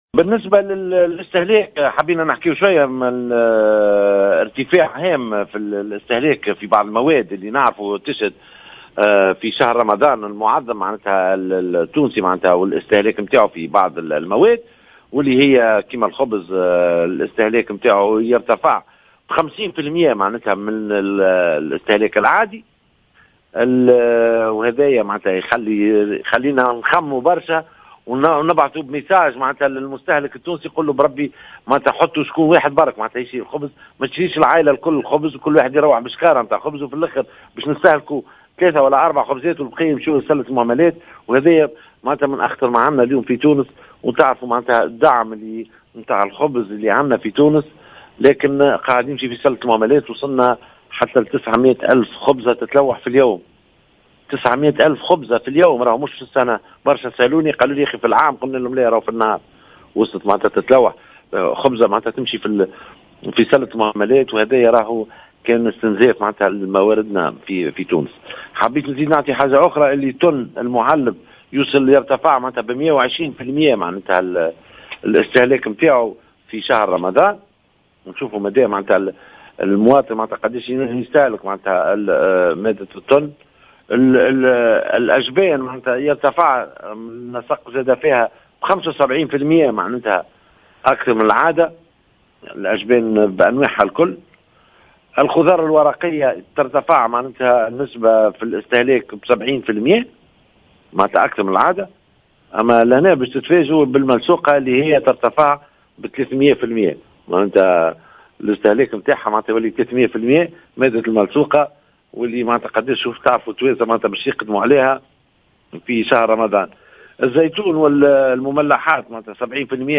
وقال في تصريح للجوهرة أف أم، إنّ معدل استهلاك الخبز خلال هذه الفترة، يسجل ارتفاعا بنسبة بـ 50 % مقارنة بالاستهلاك العادي، ويتم خلال شهر رمضان، إلقاء 9000 خبزة يوميا في سلّة المهملات، داعيا في هذا الصدد العائلة التونسية للحدّ من شراء كميات كبيرة من هذه المادة دون استهلاكها، مضيفا بالقول " حُطّوا واحد برك يِشري الخبز مش العائلة الكل ترّوح بشكارة خبز باش نستهلكوا 3 خُبزات".